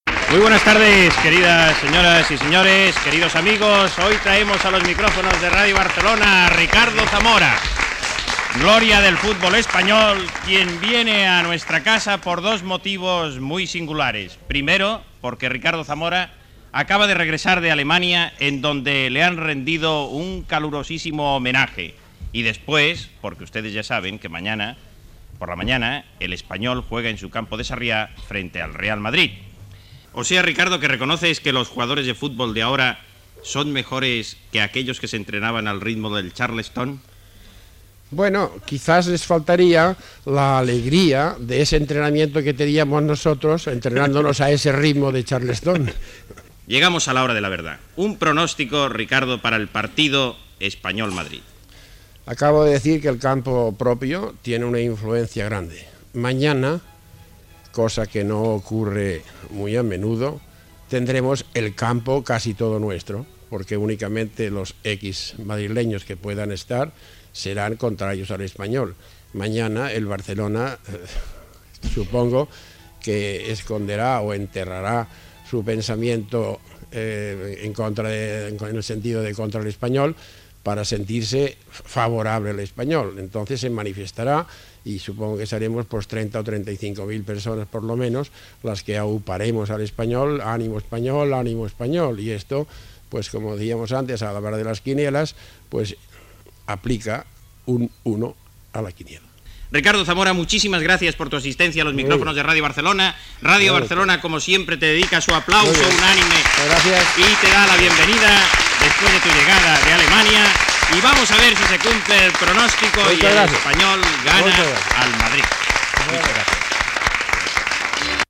Entrevista a l'exjugador de futbol Ricardo Zamora, arribat d'Alemanya
Esportiu